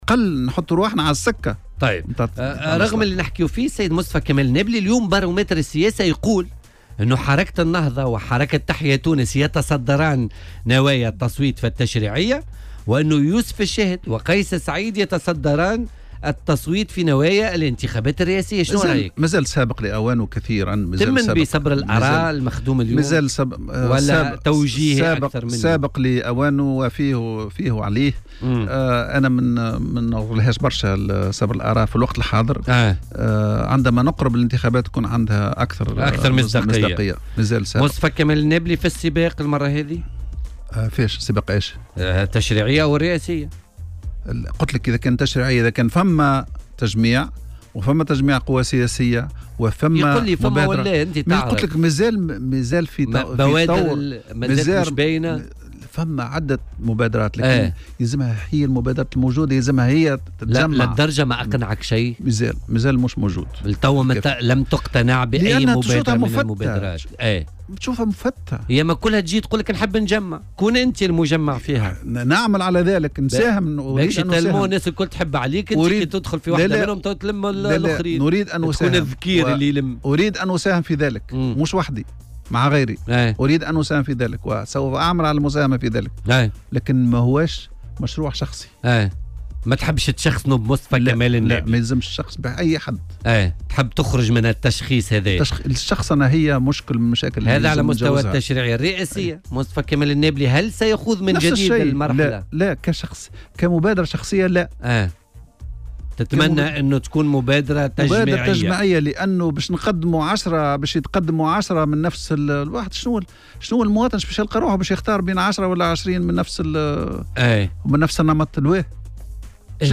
وأضاف ضيف "بوليتيكا" على "الجوهرة اف أم" إنه لا يهتم بهذه النتائج مستدركا بالقول إنها قد تصبح اكثر مصداقية باقتراب الانتخابات فعليا.